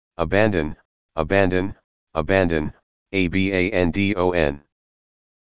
英文單字讀3次加1次單字字母發音是這樣(美國男聲)